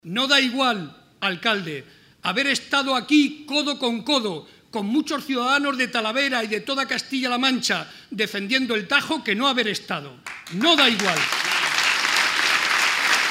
Barreda junto a Rivas en el acto celebrado en Talavera.
Barreda hizo estas manifestaciones en Talavera de la Reina, durante la presentación de la candidatura a la Alcaldía que encabeza José Francisco Rivas y que ha tenido lugar en el Instituto “Juan Antonio Castro”, donde el actual alcalde se ha formado.